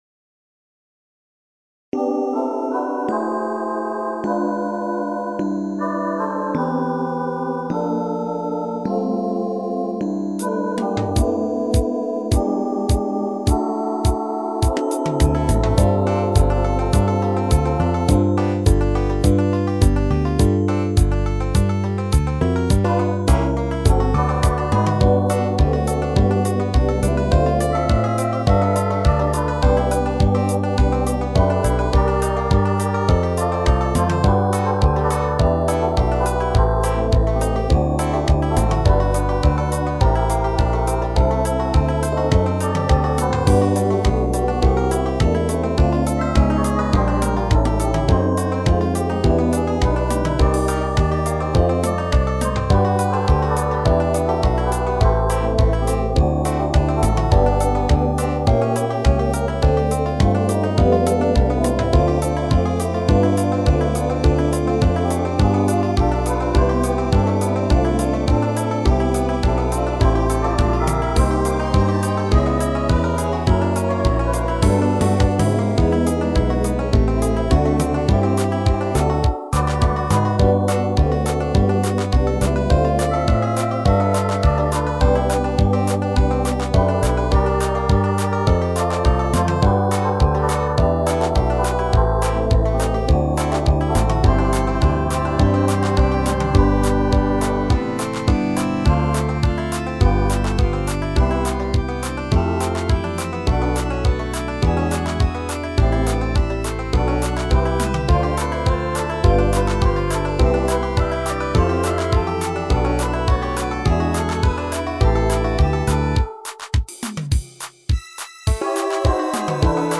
唯一私が演奏できる楽器である5弦バンジョーをフィーチャーして
原曲とは違うハイテンポに仕上げてみました。
後半のバロック部分ではバンジョーをチェンバロ風に使ってます。